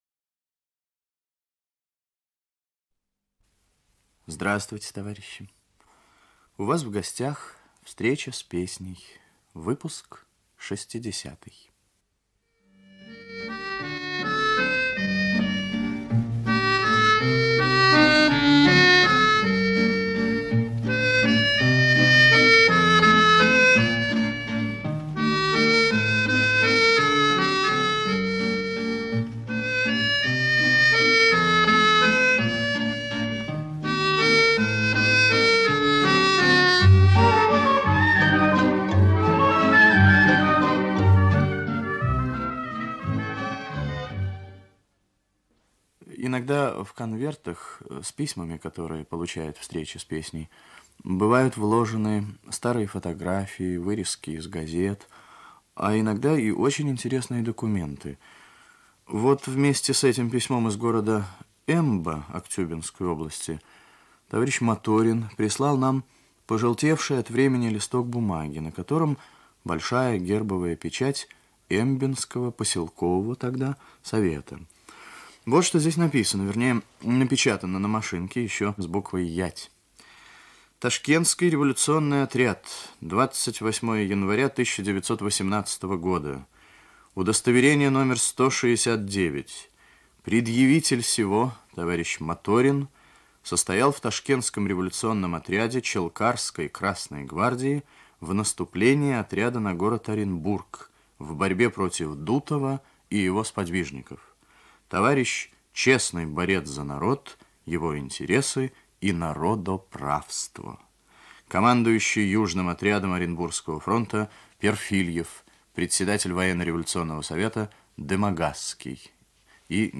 Ведущий - Виктор Татарский 1.
оркестр. 4.
в сопровождении фортепиано. 7.